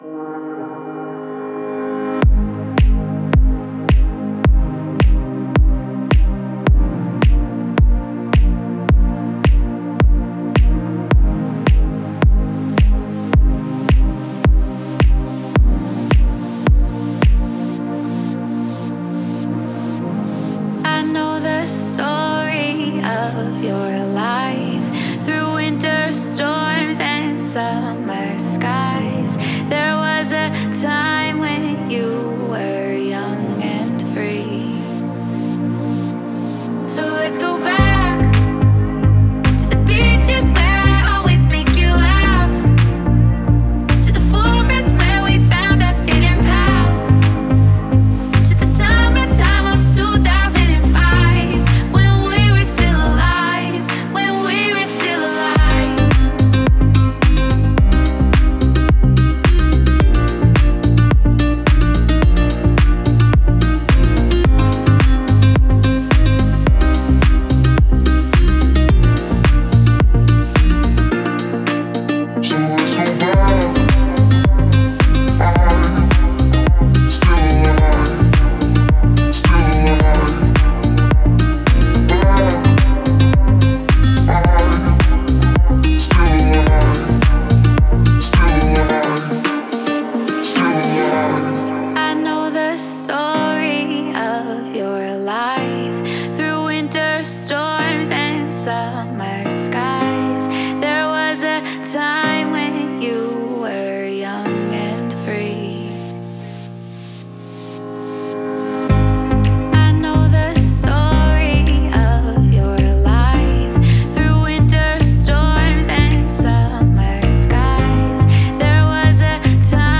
это мощная композиция в жанре поп-рок